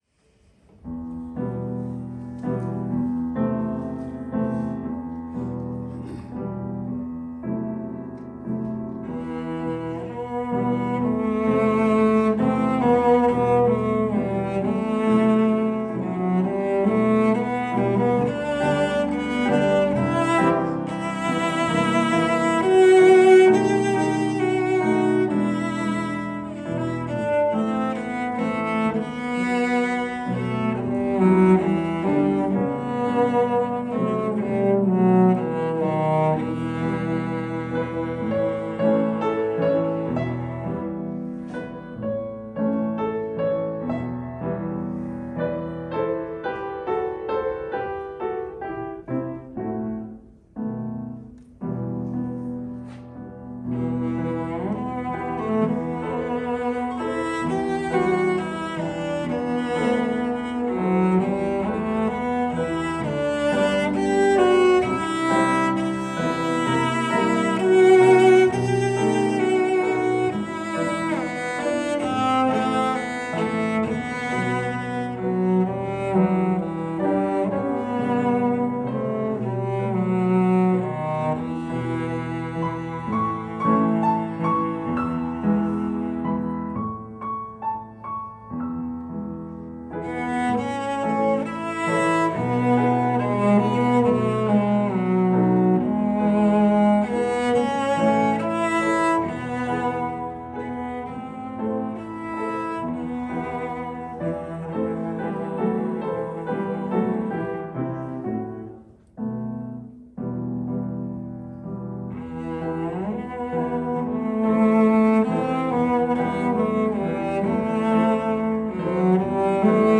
Last night was my cello teacher's studio recital and I played two pieces.
cello
I started with the first movement (1st part of 3) of a Sonata for cello and piano by Debussy. It is a gorgeous piece that ends with anticipation of more to follow.
with piano accompaniment